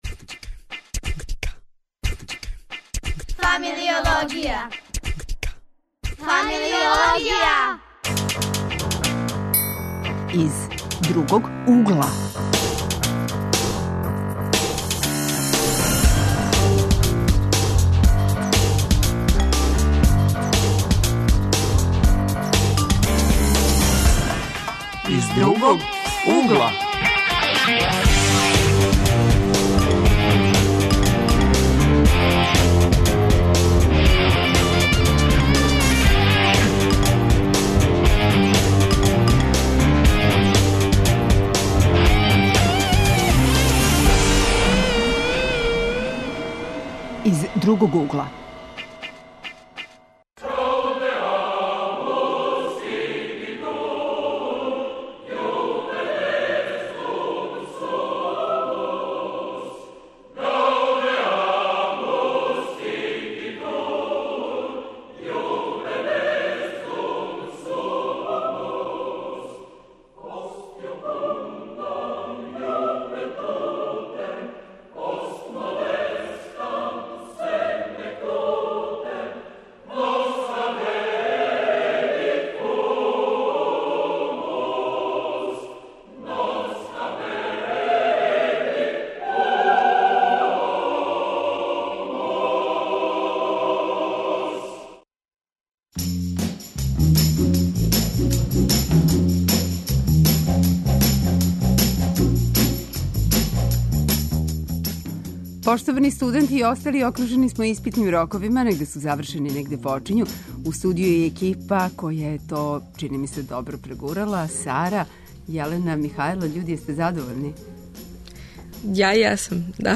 У знаку смо лепше или макар духовитије стране испитног рока - гости су студенти из Београда.